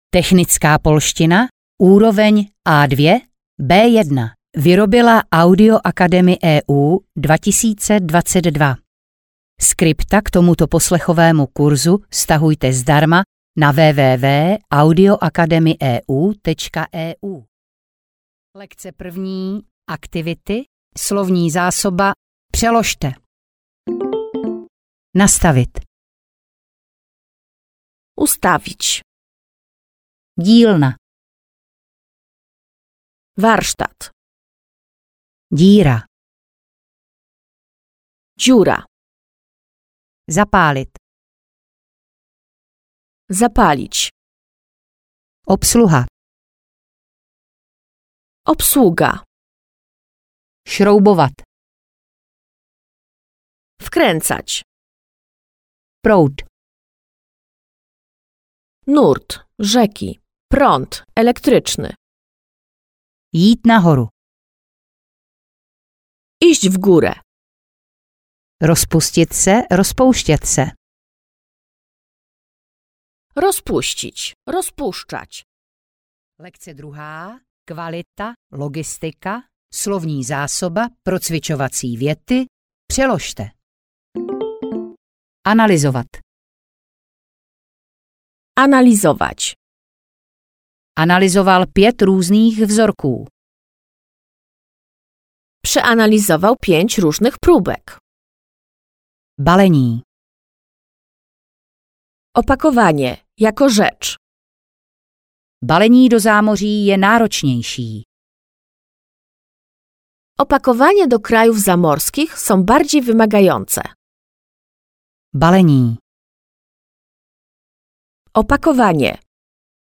Technická polština audiokniha
Ukázka z knihy